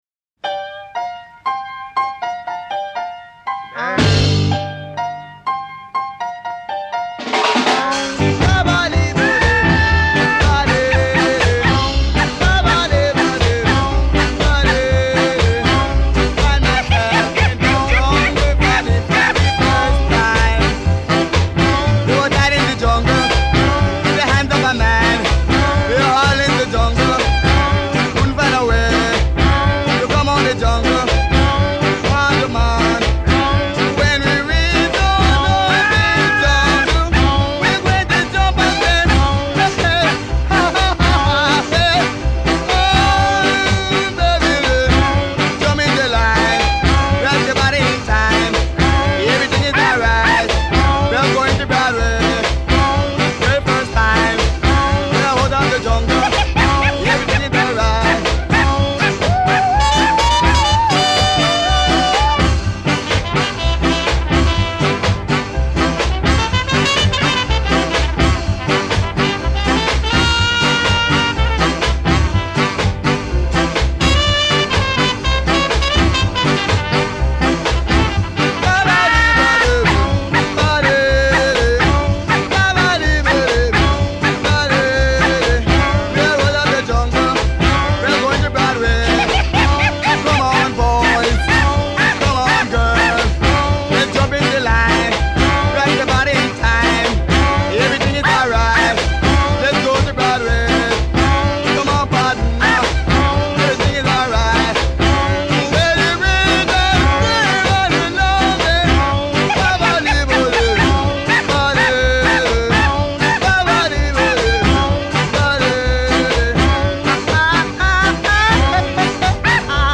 Tag: ska
incessant, insistent ska